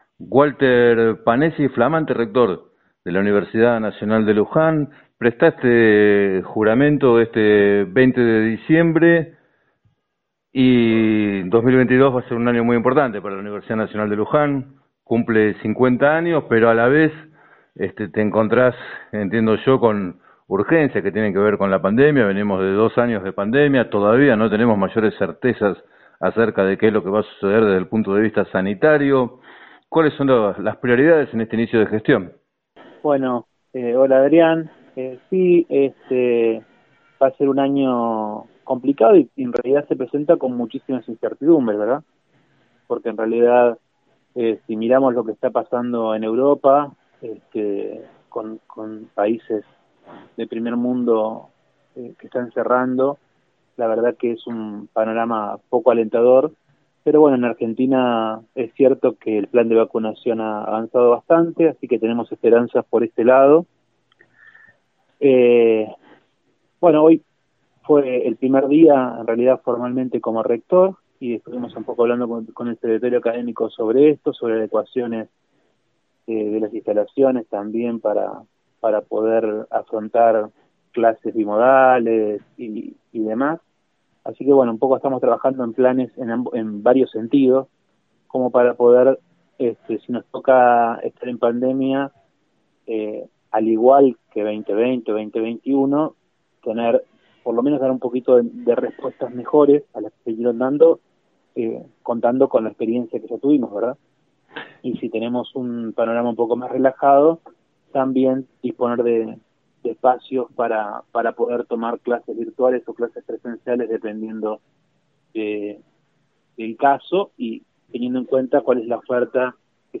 En declaraciones al programa “7 a 9” de FM Líder, Panessi expresó sus esperanzas en los avances del plan de vacunación pero señaló la necesidad de “anticipar escenarios” y asegurar para el inicio del ciclo, en marzo, los espacios físicos y el equipamiento necesarios para la bimodalidad.